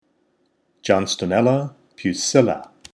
Pronunciation/Pronunciación: John-sto-nél-la pu-síl-la Etymology/Etimología: "very small" Synonyms/Sinónimos: Homotypic Synonyms: Eritrichium pusillum Torr.